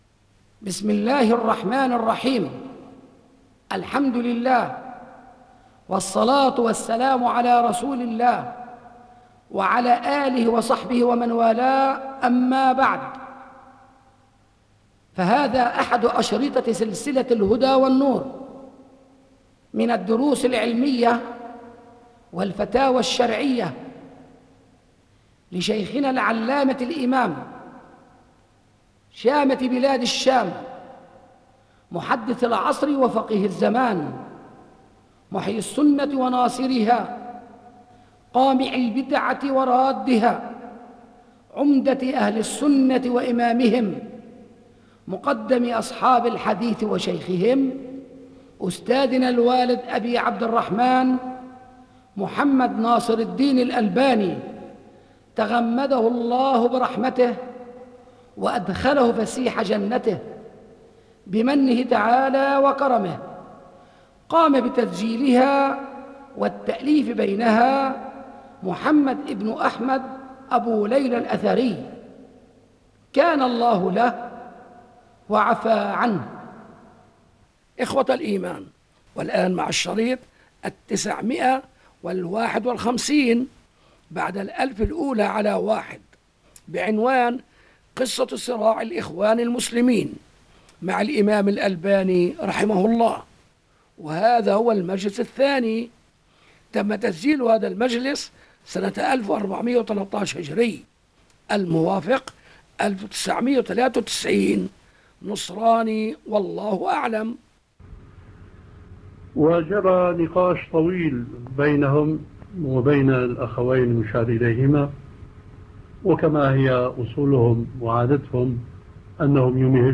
بِصوتِ الإِمامِ الألبَانِي